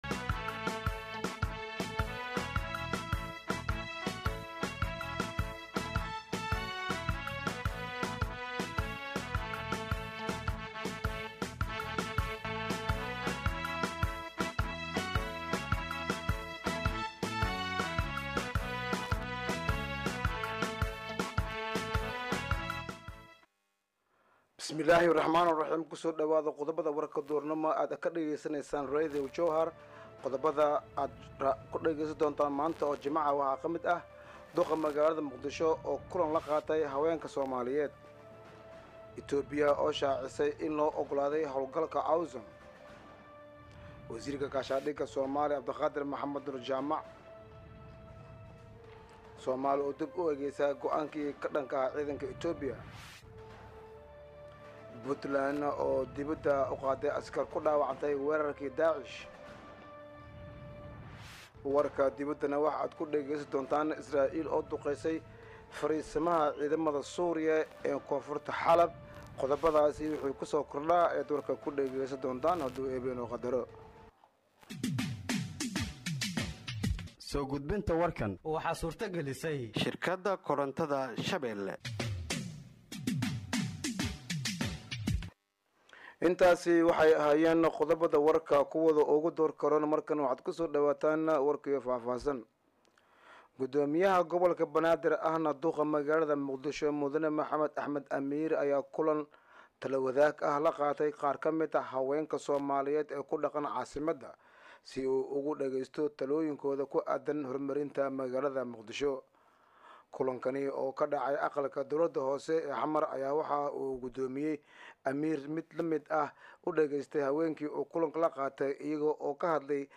Dhageeyso Warka Duhurnimo ee Radiojowhar 03/01/2025
Halkaan Hoose ka Dhageeyso Warka Duhurnimo ee Radiojowhar